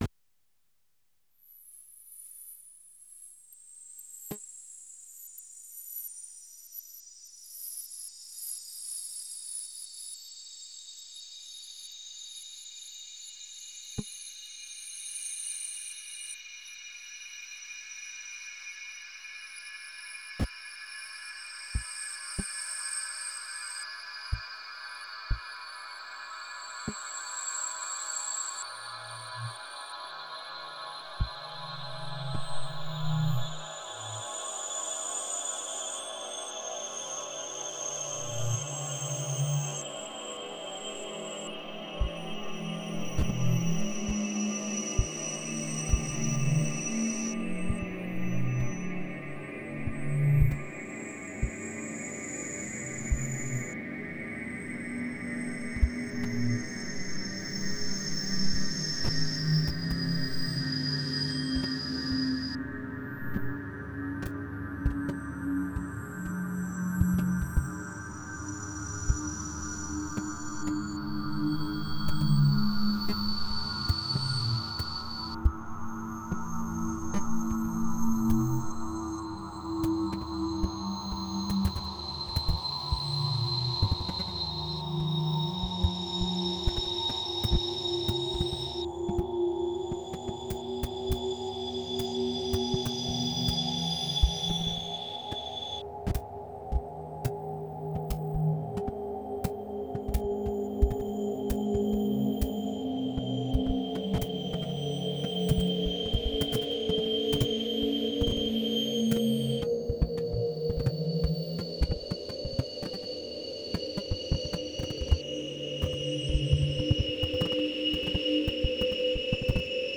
monophonic digital audio